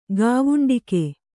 ♪ gāvuṇḍike